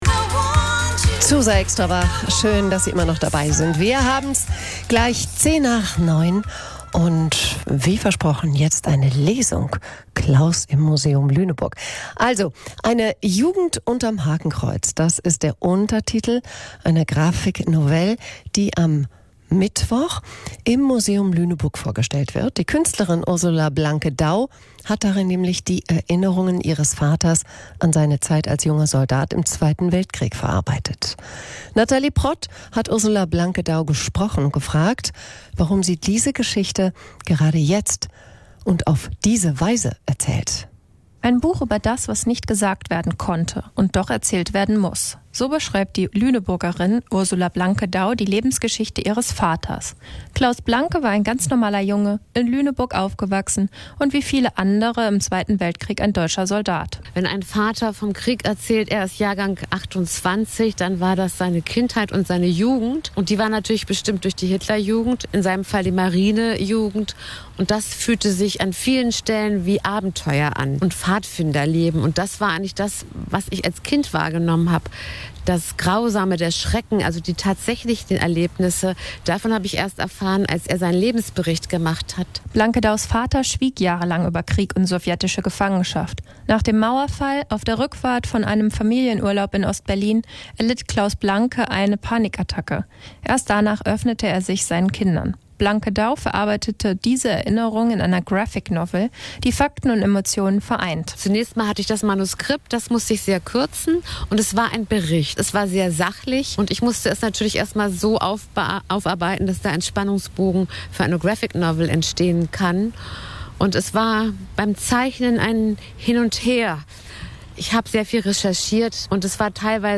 Interview
Erste Ausstrahlung Radio ZUSA am 15.09.2025.